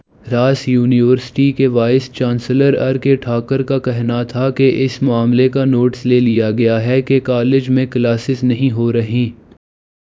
deepfake_detection_dataset_urdu / Spoofed_TTS /Speaker_06 /106.wav